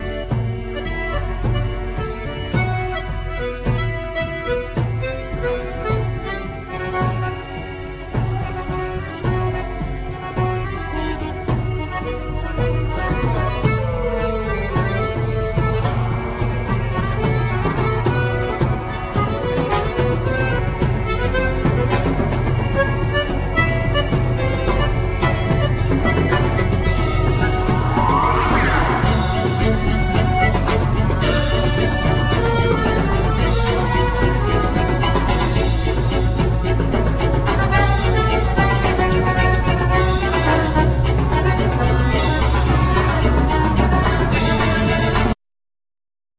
Accordeon,Vocal
Violin,Viola
Keyboards,Vocals
Electric & Acoustic Bass,Guiro
Vibraphone,Marimba,Glockenspiel,Percussion
Drums,Percussion